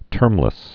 (tûrmlĭs)